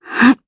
Gremory-Vox_Casting1_jp.wav